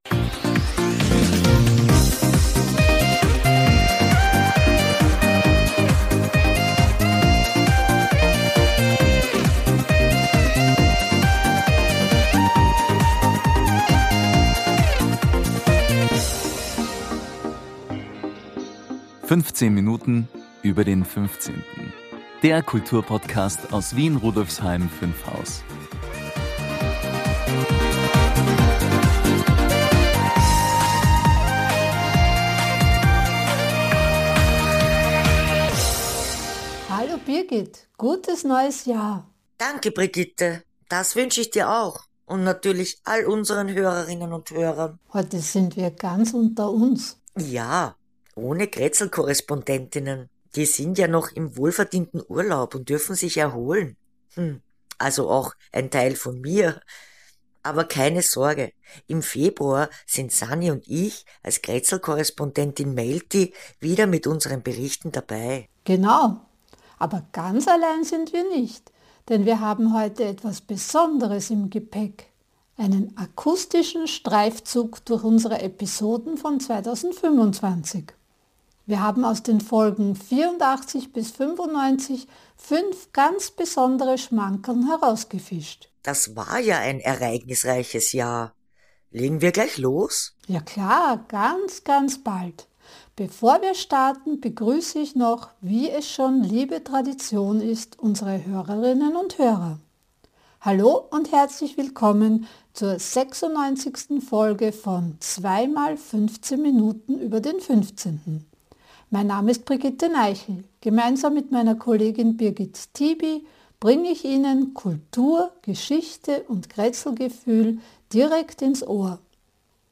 Wir präsentieren eine Collage aus den spannendsten „Schmankerln“ von 2025: Erfahren Sie, warum Dokumente über die französische Besatzung in Wien so schwer zu finden sind und wie ein einfacher Brief und Infos aus dem Telefonbuch dazu führte, den Schöpfer unseres Fliesenmosaiks in der Museumsbar ausfindig zu machen.